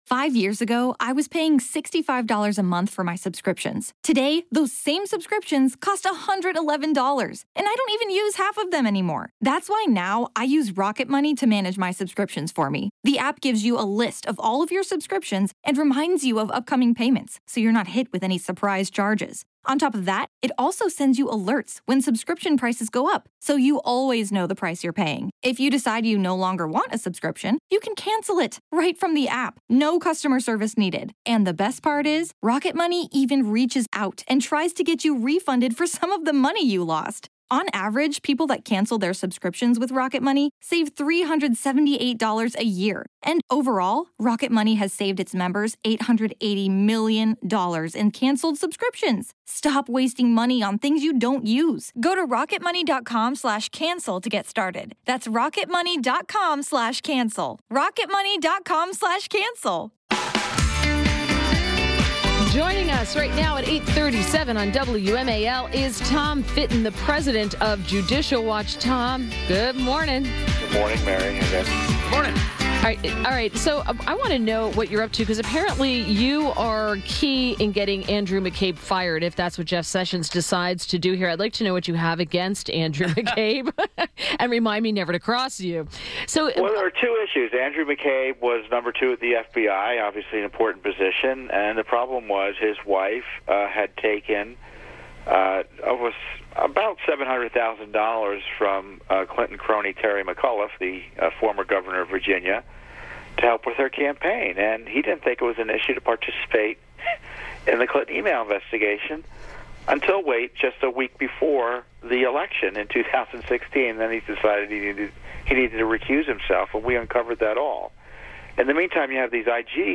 WMAL Interview - TOM FITTON - 03.15.18